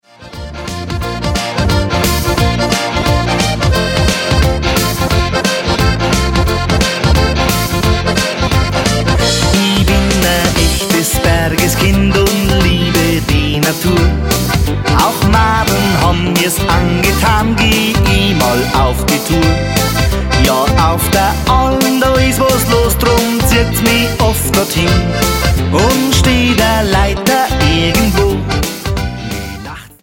Genre: Volkstümliche Musik
Gesang, Gitarre, Dudelsack
Akkordeon, Steirische, Keyboards
Gesang, Drums
Gesang, Geige